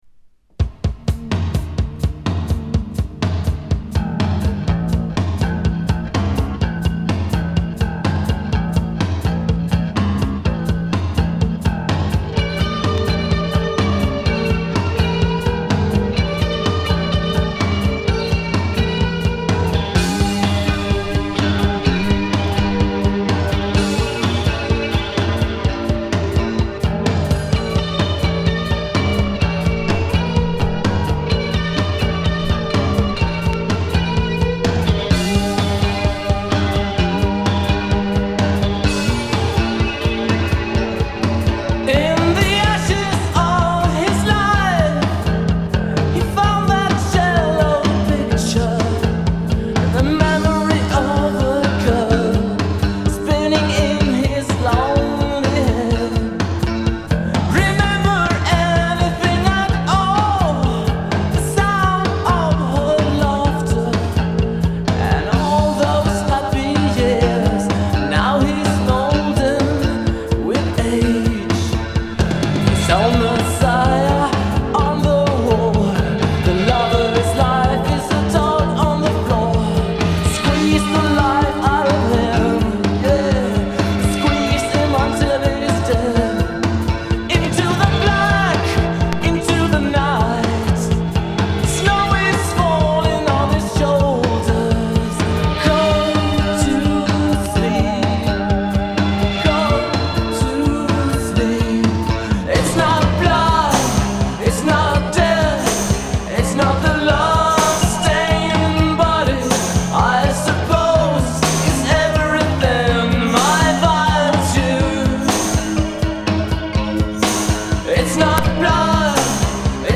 Swedish postpunks